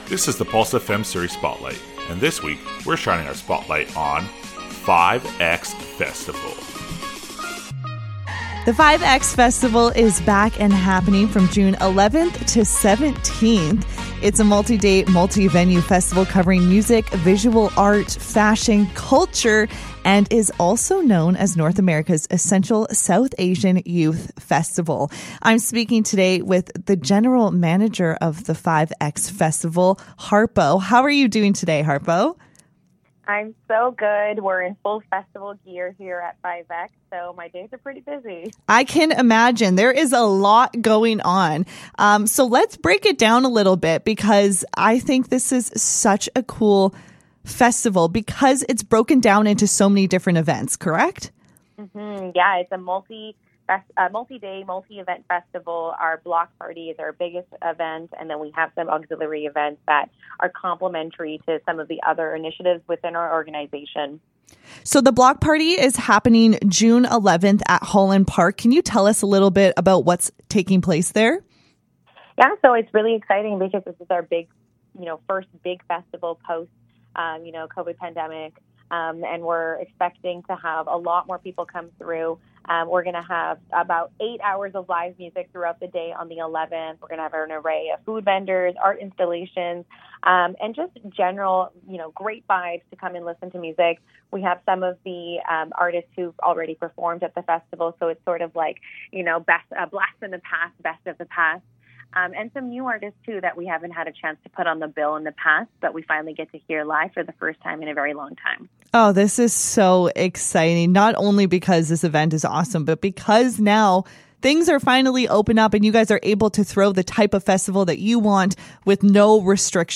5x-Interview.mp3